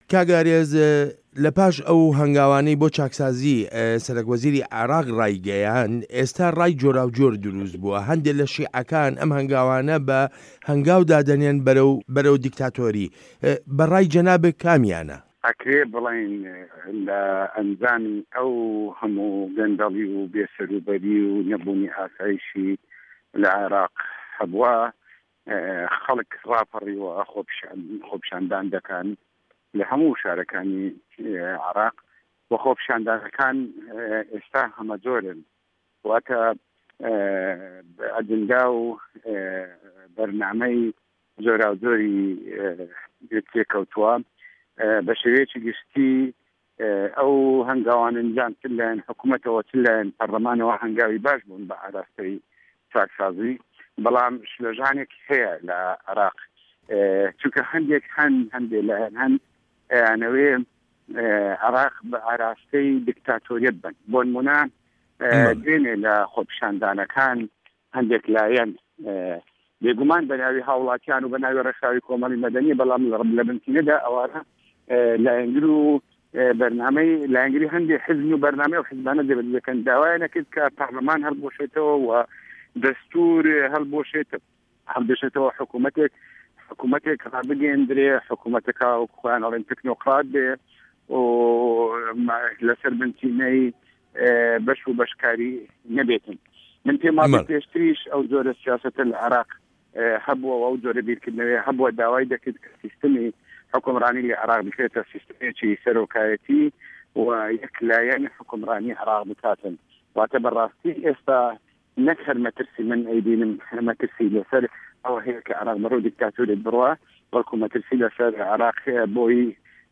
وتووێژ لەگەڵ ئارێز عەبدوڵا